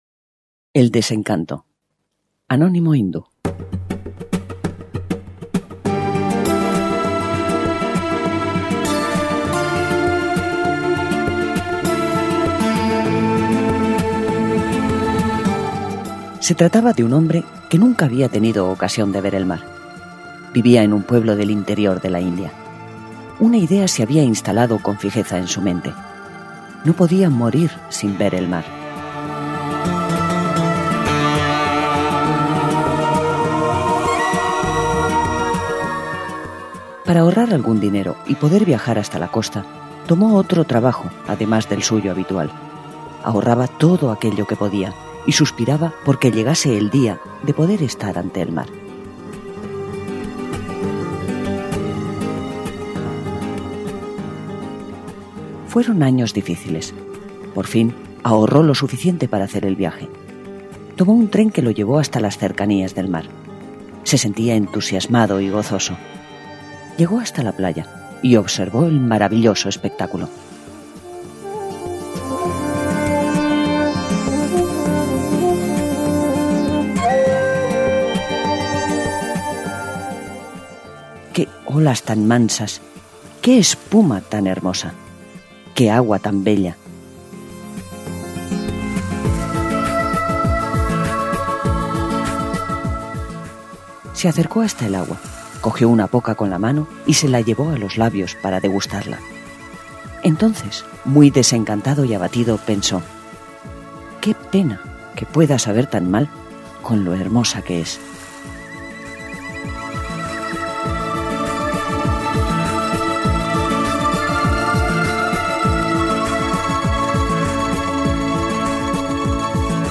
Audiolibro: El desencanto, cuento hindú
Música: Caerou (cc:by-sa)